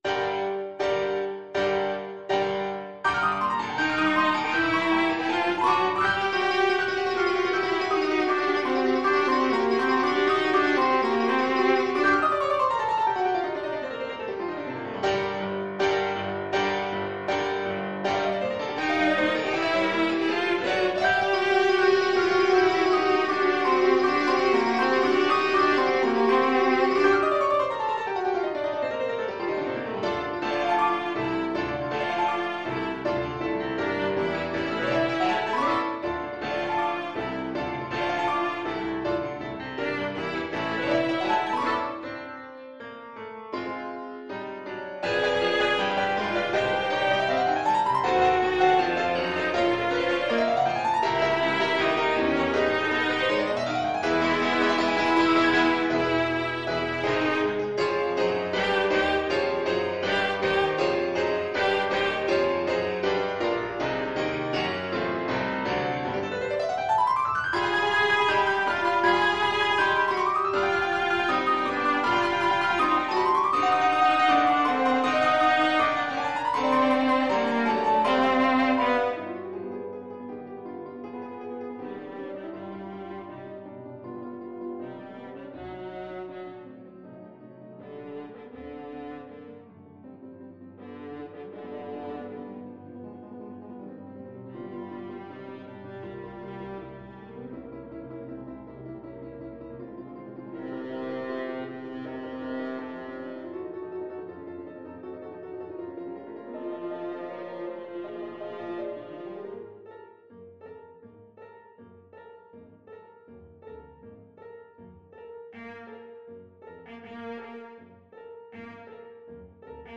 Classical Verdi, Giuseppe Dies Irae from Requiem Viola version
Play (or use space bar on your keyboard) Pause Music Playalong - Piano Accompaniment Playalong Band Accompaniment not yet available reset tempo print settings full screen
Allegro agitato (=80) (View more music marked Allegro)
4/4 (View more 4/4 Music)
G minor (Sounding Pitch) (View more G minor Music for Viola )
Viola  (View more Intermediate Viola Music)
Classical (View more Classical Viola Music)